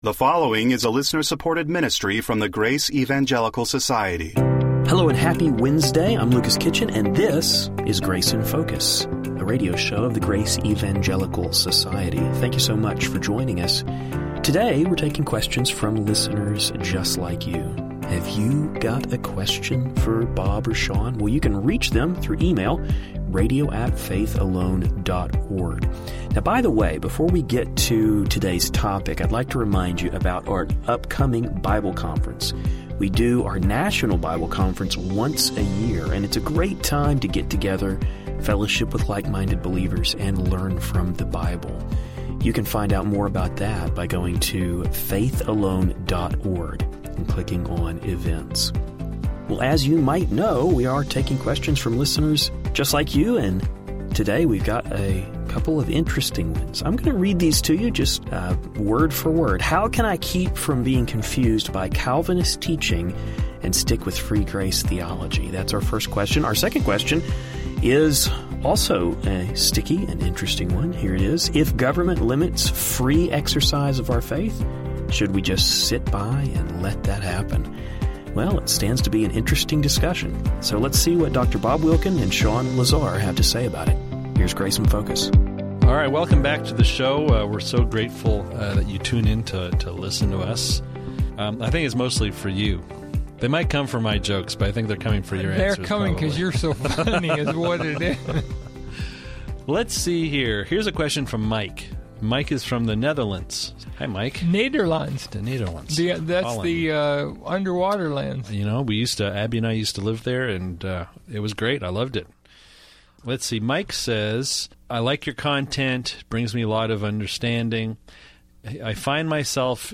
We will hear the guys address a question related to Calvinism. Specifically, how can we keep ourselves from confusion if we attend a church that teaches Calvisim?